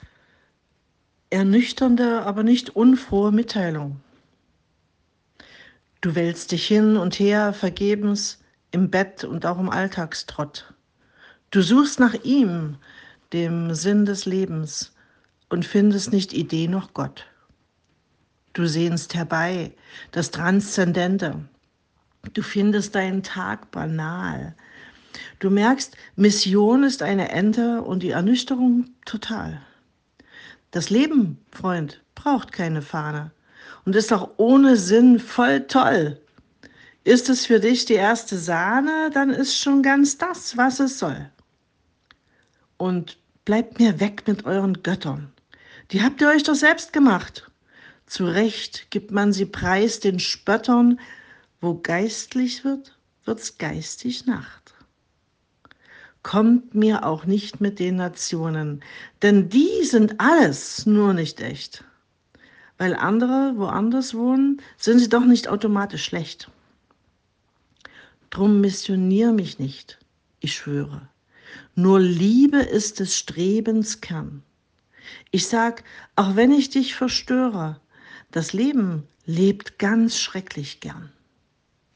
🎤 Audio Lesung